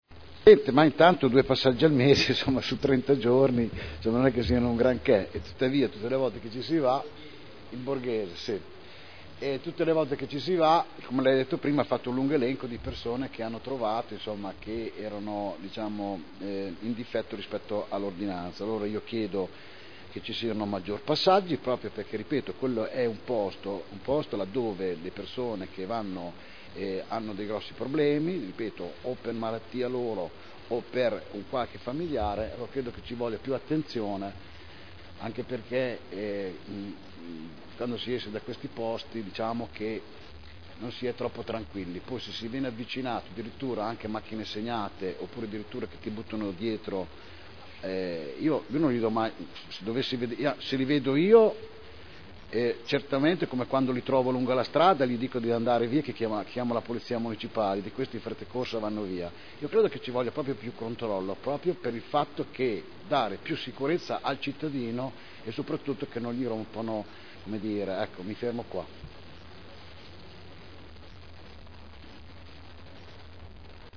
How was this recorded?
Seduta del 15/02/2010